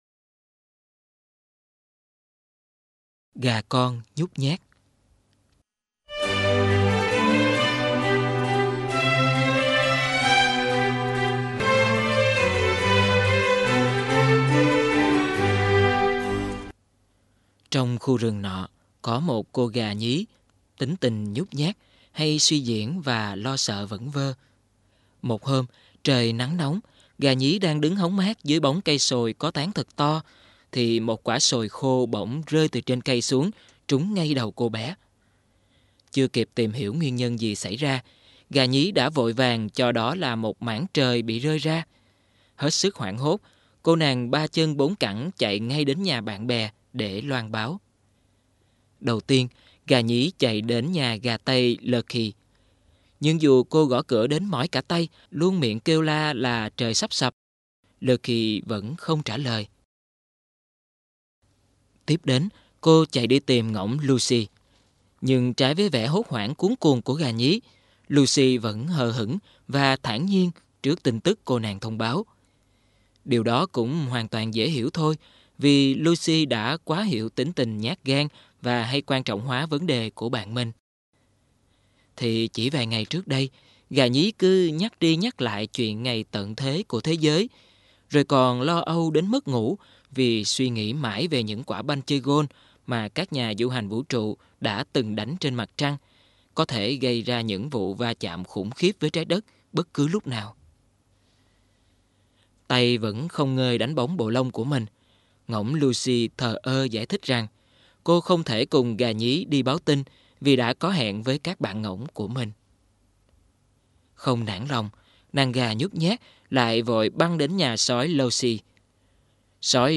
Sách nói Những Câu Chuyện Không Phải Là Cổ Tích - Sue Gallehugh - Sách Nói Online Hay